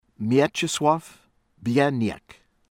BIELECKI, JAN KRZYSZTOF YAHN   K-SHIHSH-tawf   b-yeh-LEHT-skee